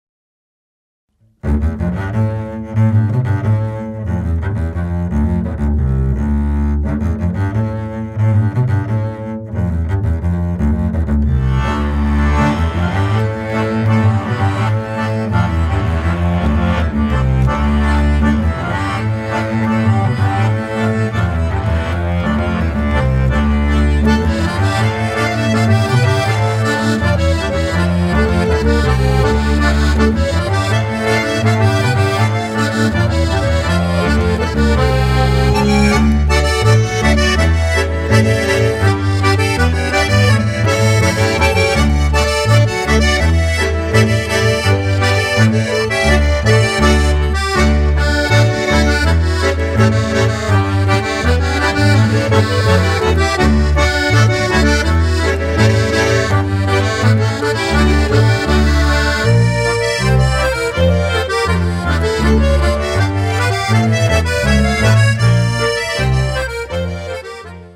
Bassklarinette
Hackbrett
trad. Schweden/Norwegen